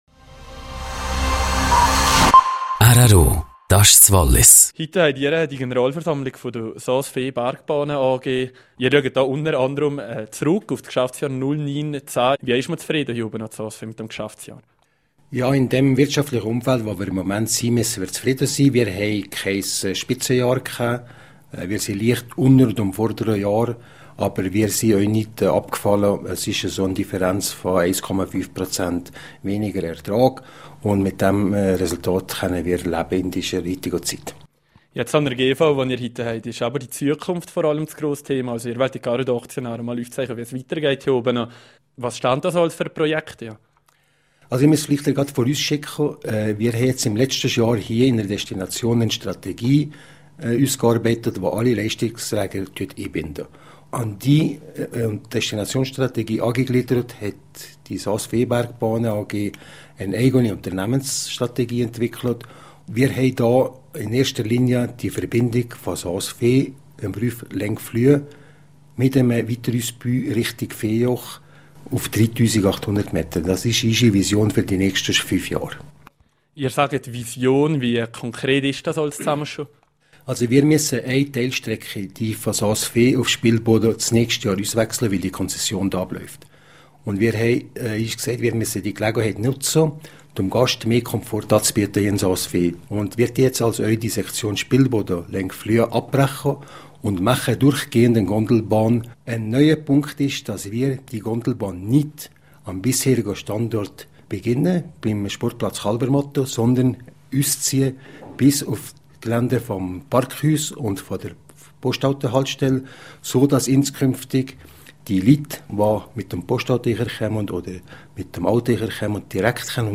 Das Interview auf rro bestätigt die Fotomontage: Es soll eine ca 4,2 km lange Gondelbahn vom Parkhaus / Postbusendstation bis Längfluh geben.